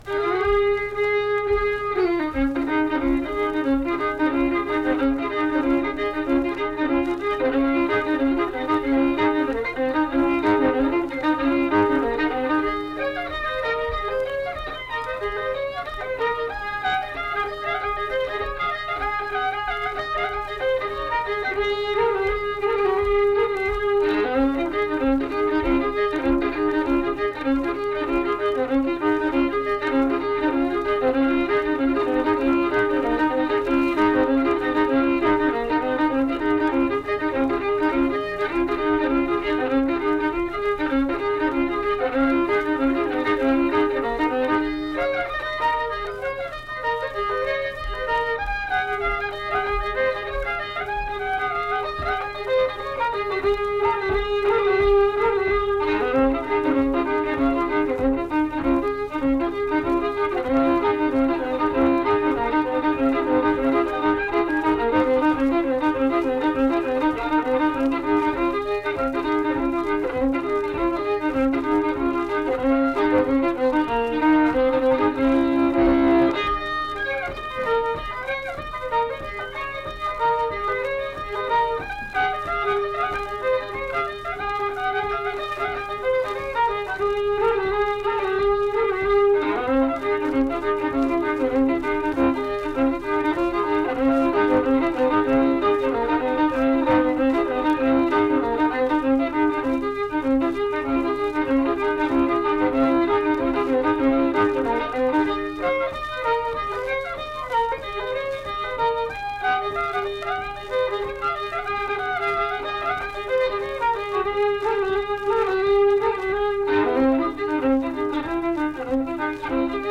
Unaccompanied fiddle music
Instrumental Music
Fiddle
Pocahontas County (W. Va.), Mill Point (W. Va.)